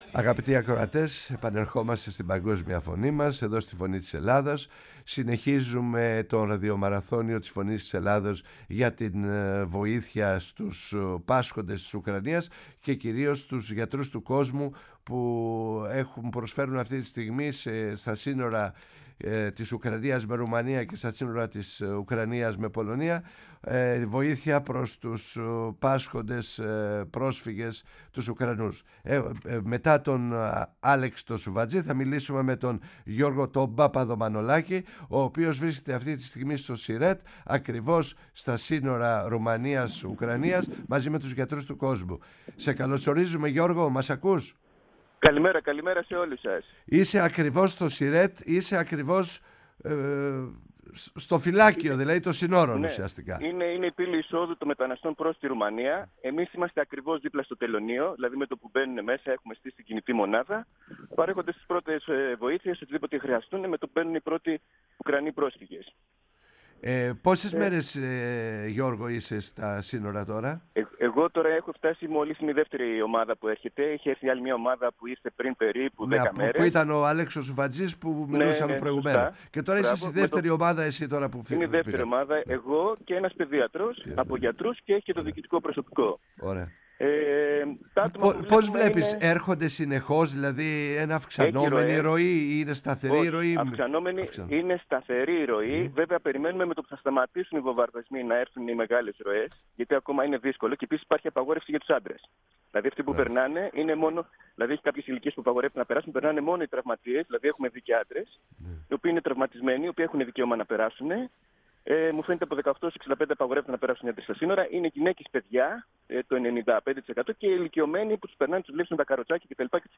Η ΦΩΝΗ ΤΗΣ ΕΛΛΑΔΑΣ Η Παγκοσμια Φωνη μας ΕΝΗΜΕΡΩΣΗ Ενημέρωση ΣΥΝΕΝΤΕΥΞΕΙΣ Συνεντεύξεις